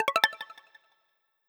OpenOrEnable4a.wav